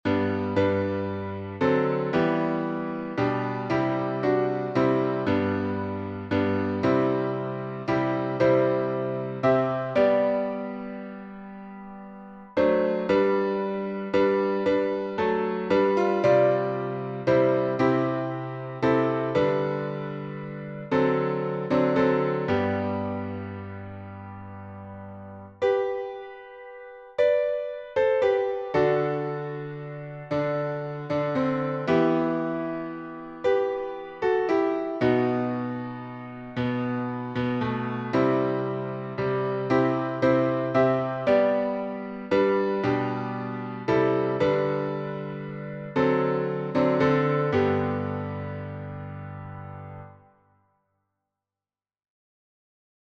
Key signature: G major (1 sharp)